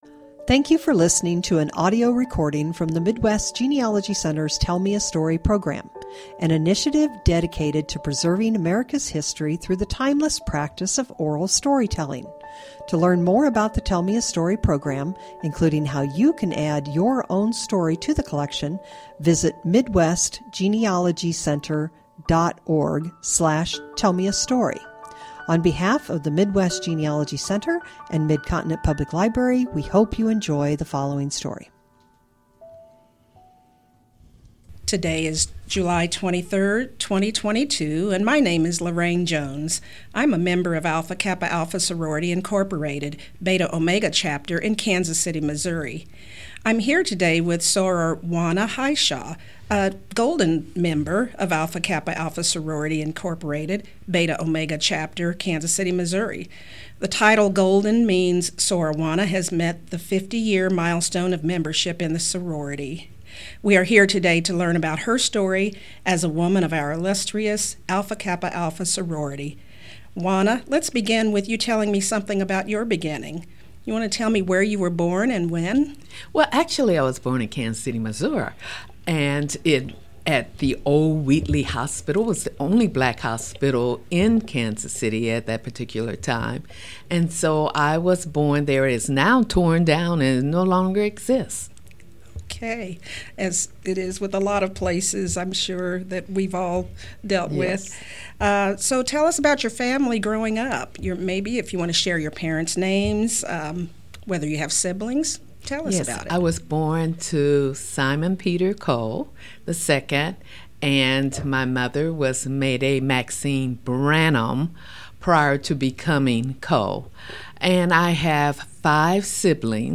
Genealogy Family history Oral history